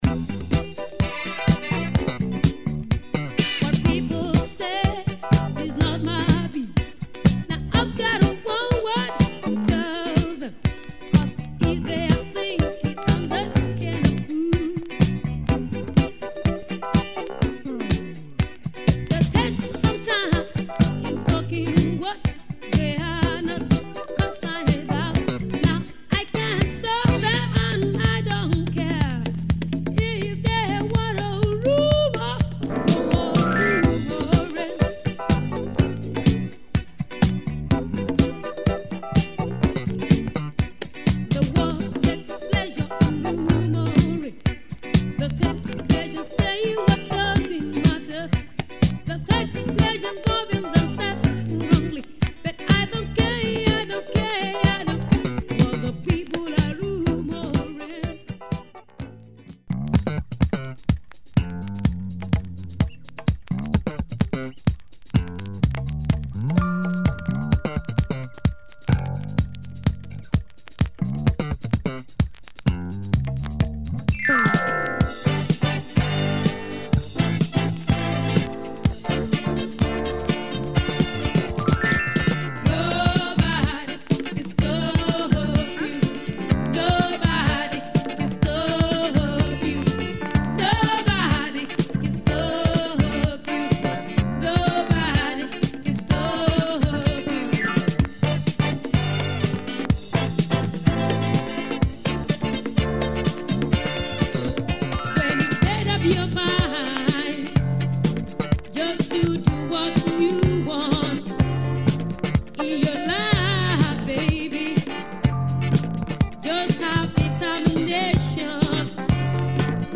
Killer Nigerian funk